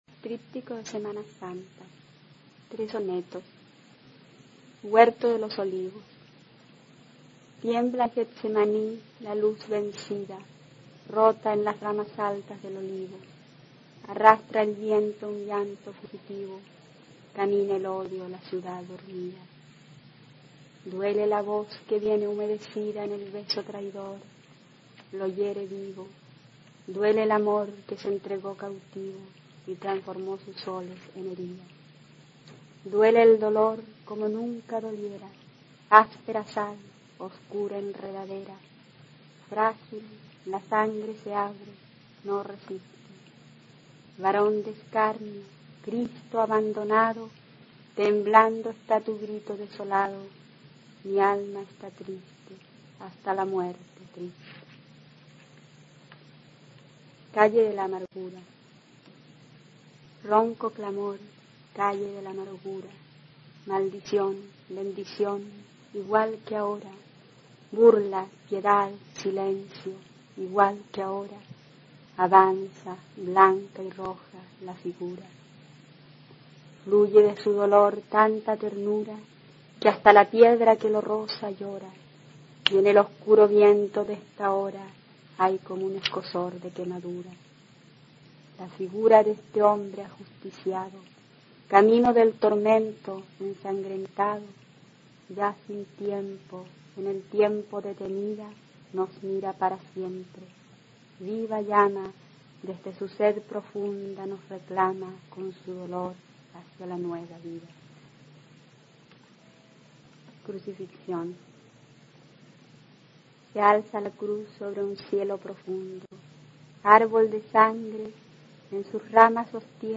Poema
Lírica